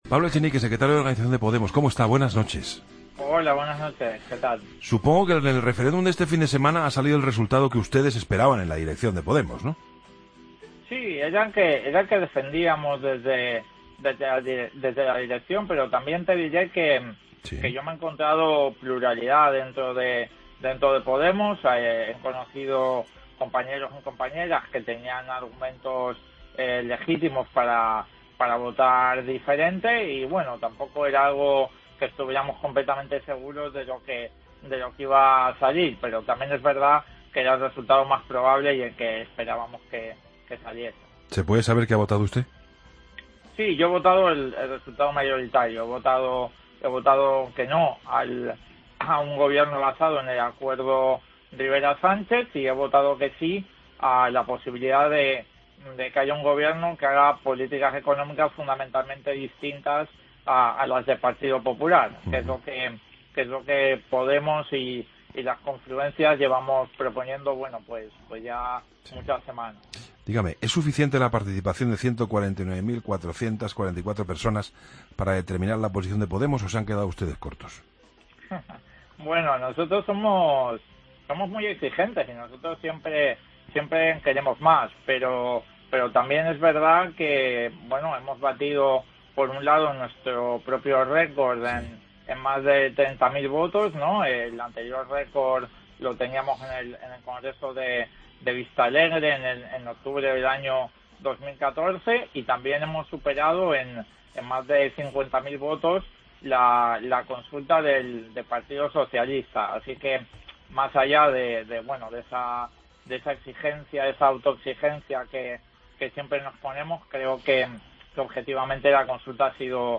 Escucha la entrevista a Pablo Echenique, secretario de Organización de Podemos en 'La Linterna'